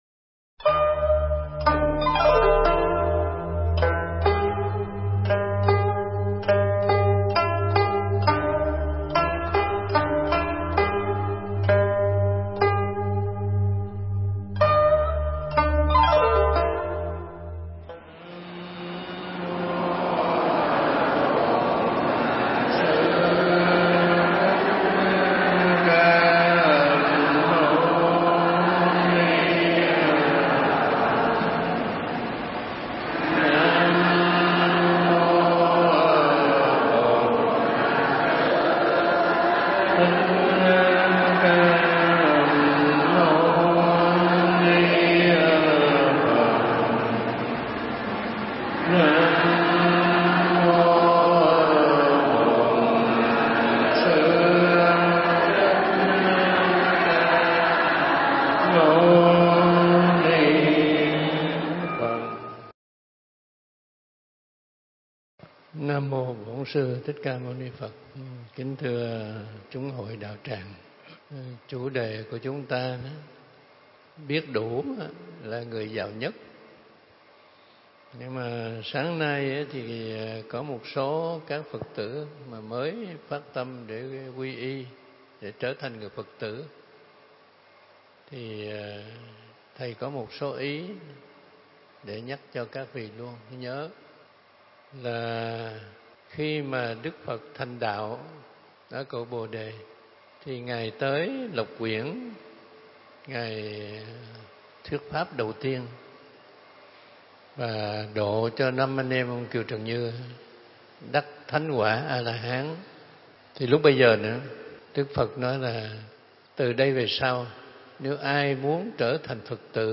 Nghe Mp3 thuyết pháp Phật Giáo Và Các Tôn Giáo Phần 1